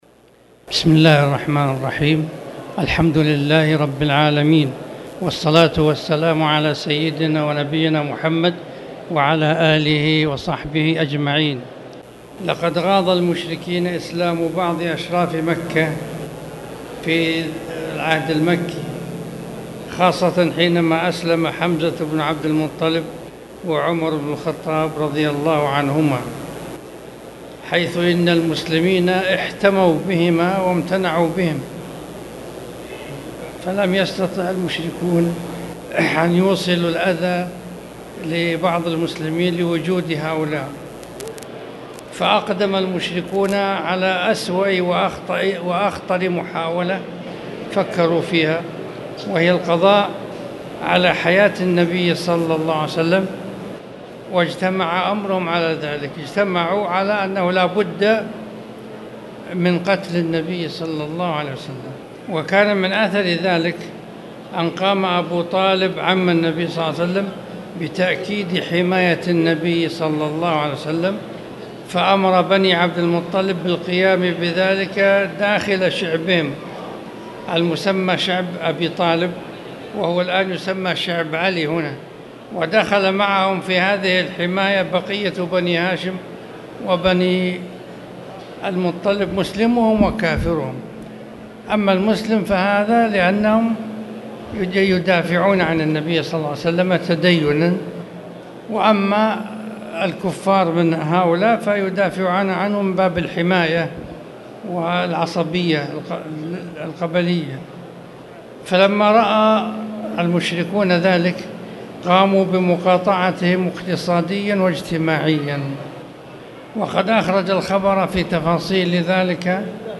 تاريخ النشر ٢٥ شعبان ١٤٣٨ هـ المكان: المسجد الحرام الشيخ